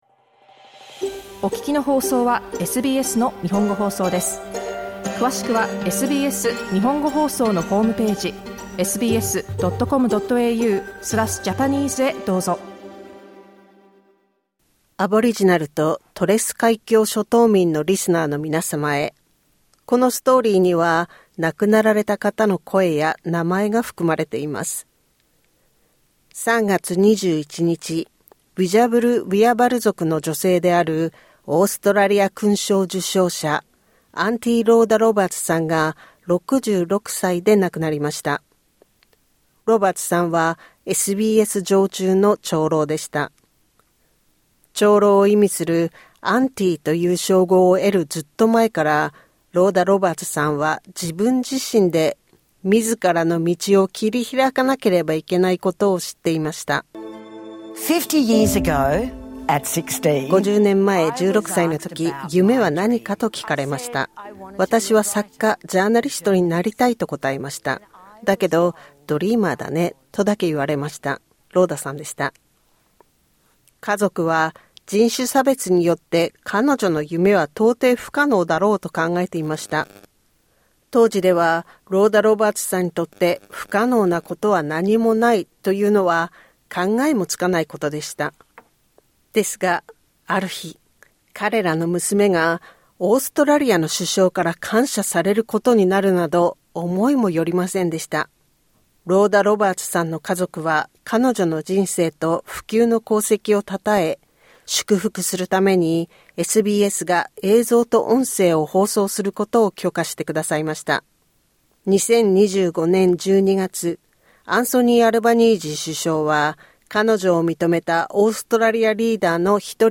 Rhoda Roberts AO SBS Elder In-Residence Oration 2024 Source: SBS
Aboriginal and Torres Strait Islander listeners are advised the following story includes the voice of a deceased person.